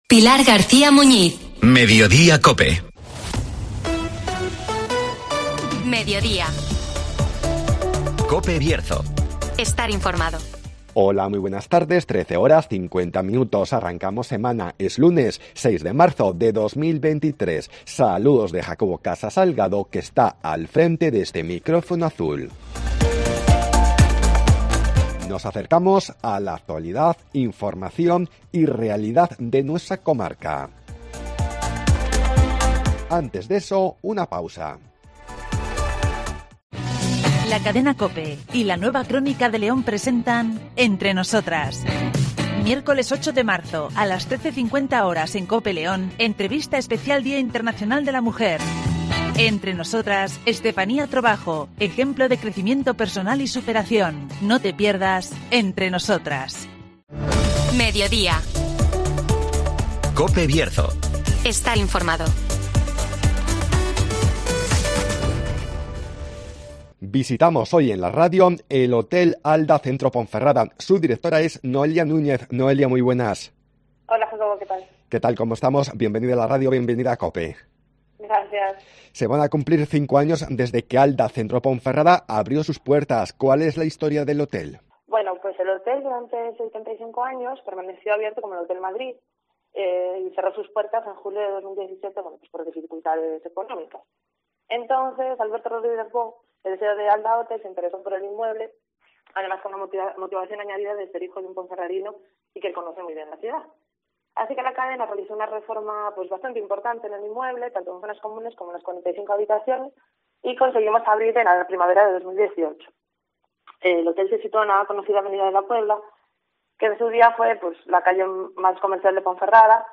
Nos acercamos al Hotel Alda Centro Ponferrada (Entrevista